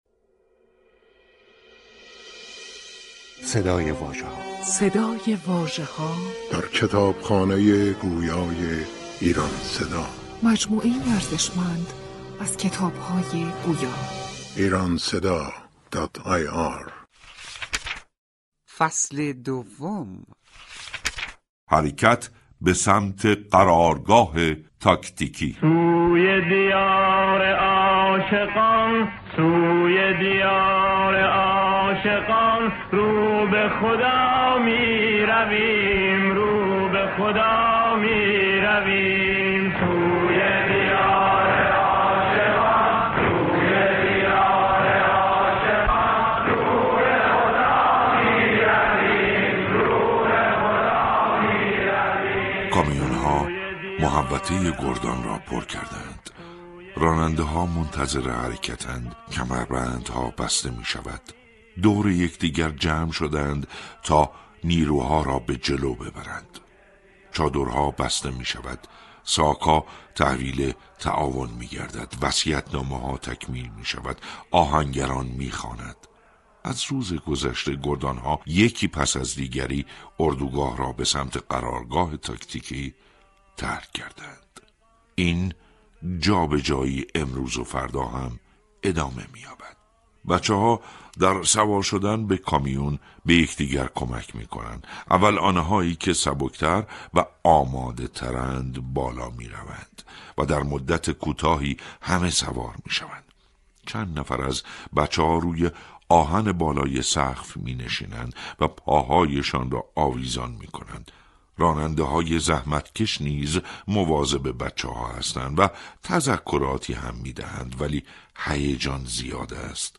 کتاب گویای «سینای شلمچه» منتشر شد